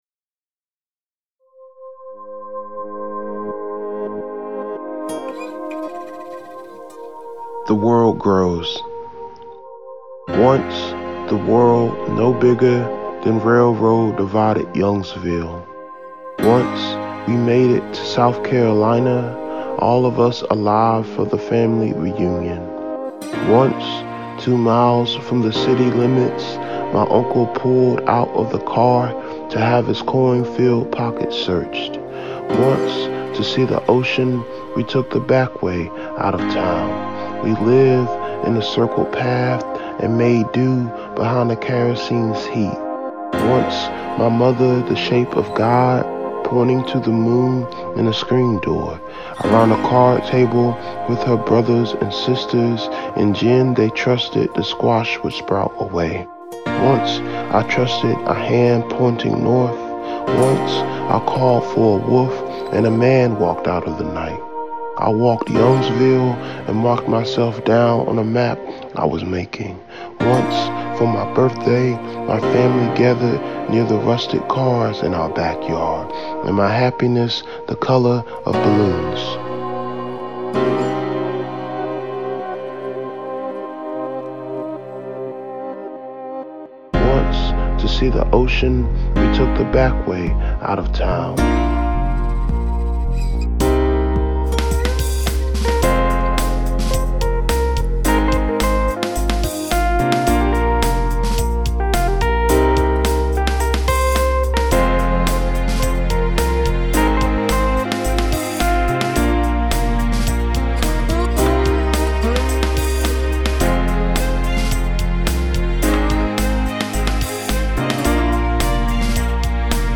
Casting the arrangement in a minor key seemed to complement the melodies of memory in the poem.
The 108 beats per minute of the outro were determined by Ableton Live’s analysis of the original audio.